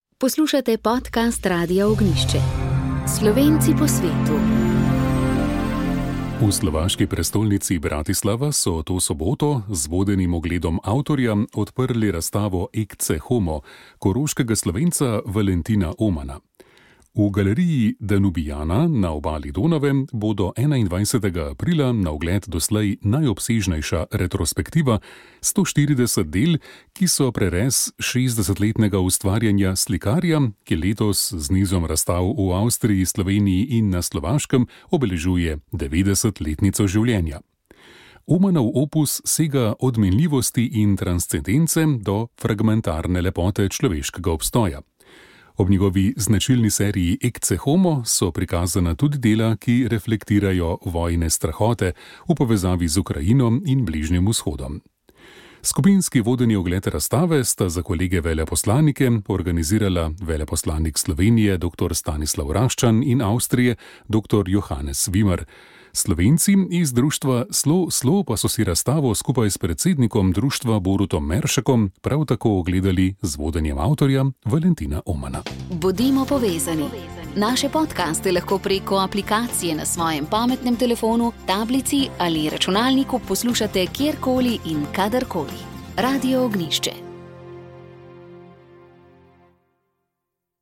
Sv. maša iz cerkve Marijinega oznanjenja na Tromostovju v Ljubljani 30. 10.